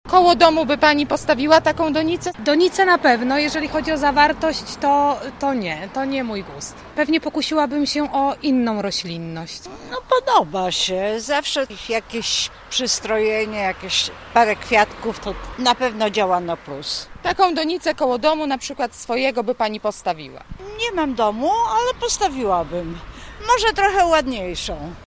Cześć mieszkańców których spotkaliśmy dziś przy ulicy Teatralnej ma jednak uwagi dotyczące wyglądu nowej ozdoby, a właściwie roślin posadzonych w donicach: